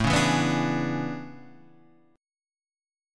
A Major 11th